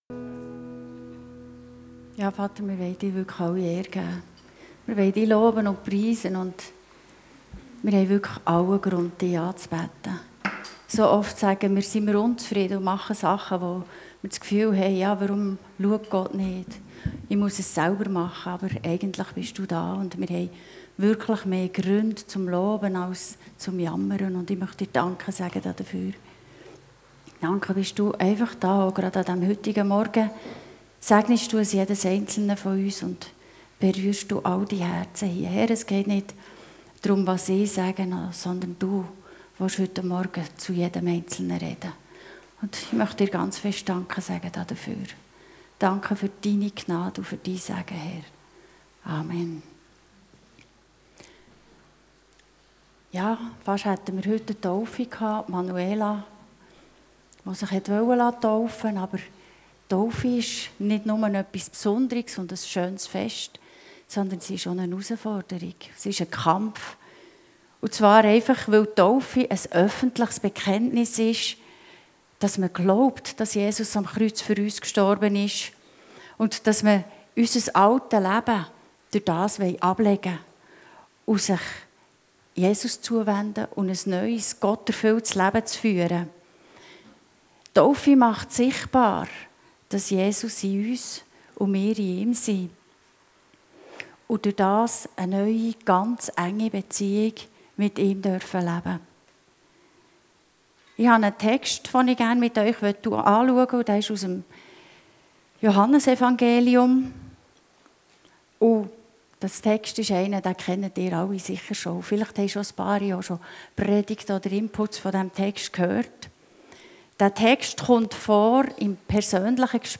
Passage: Johannes 15, 1-11 Dienstart: Gottesdienst